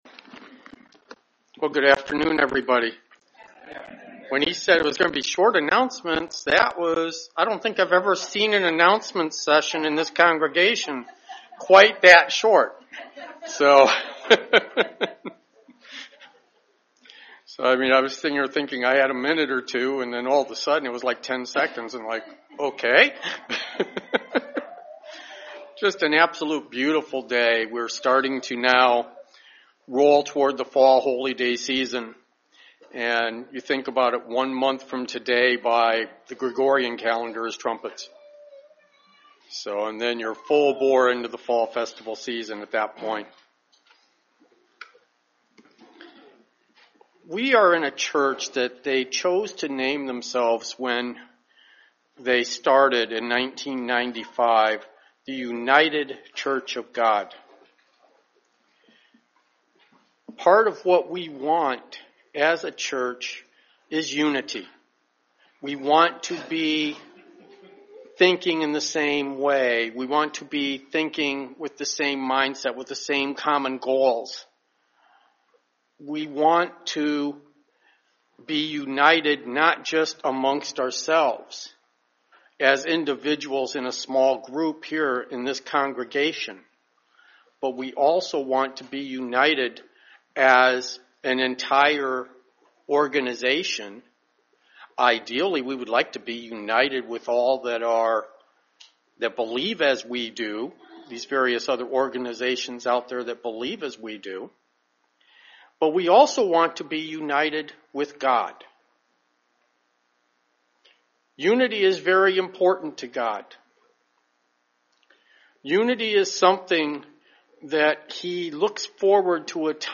The first half of Ephesians 4 has a lot to say about unity. This sermon will discus the unity that we are to have in our lives.